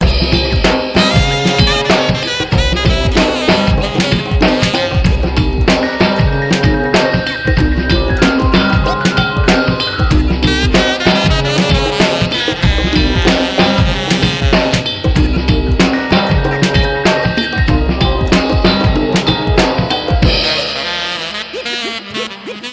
Это сыграно проф. музыкантами.